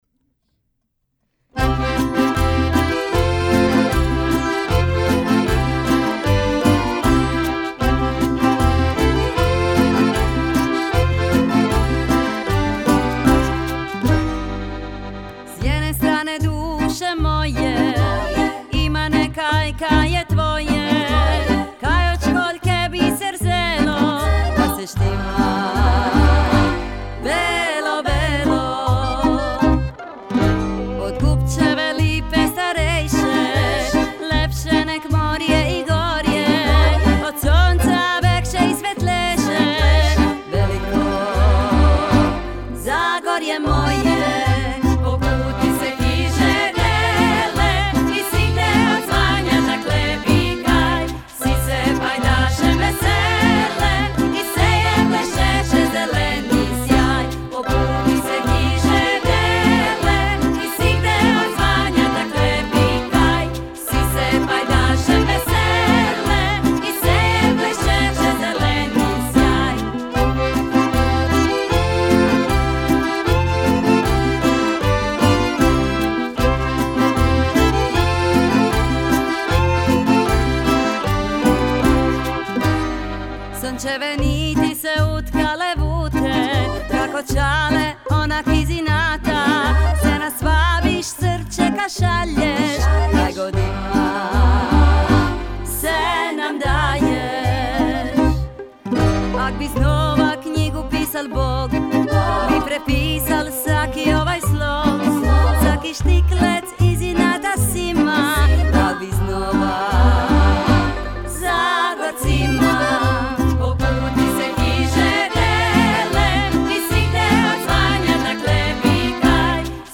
predložio za izvođenje jednu od ponajboljih ženskih skupina